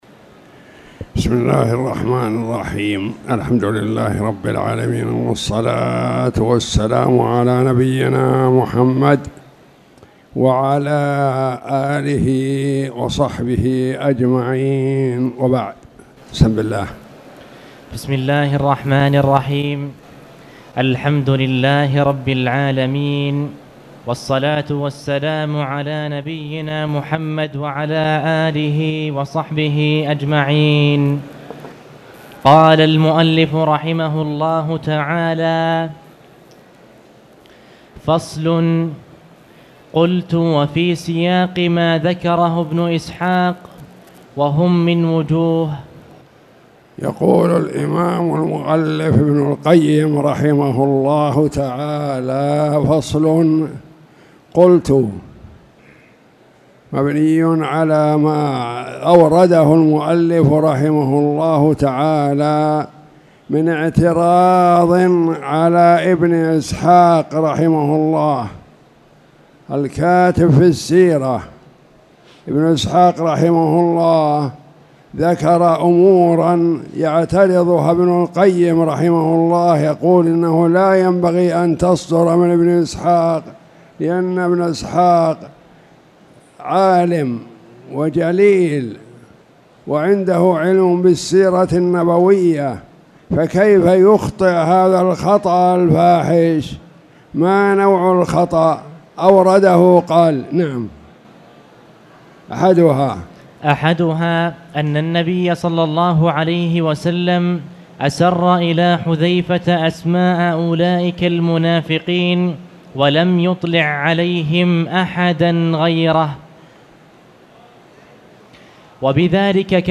تاريخ النشر ١٨ صفر ١٤٣٨ هـ المكان: المسجد الحرام الشيخ